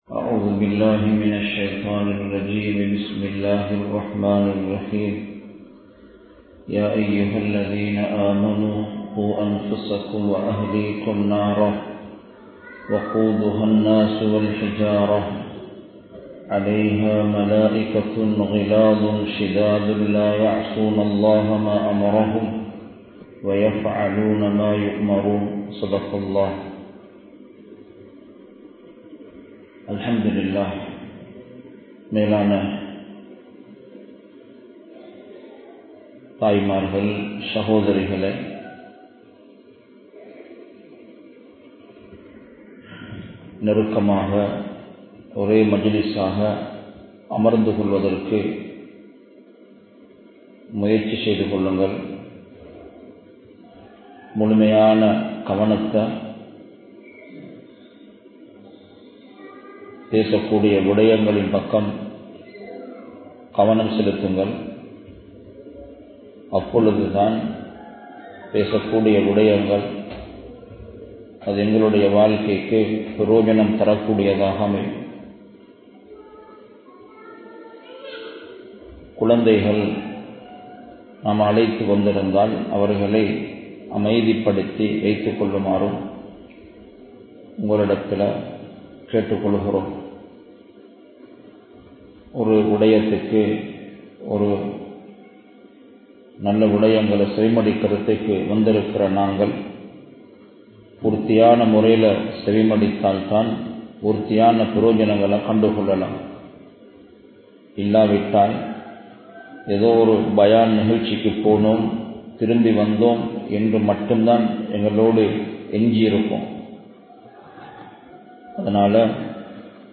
Soathanaihal Ean Varuhintrana? (சோதனைகள் ஏன் வருகின்றன?) | Audio Bayans | All Ceylon Muslim Youth Community | Addalaichenai